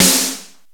LYNOS SNARE.wav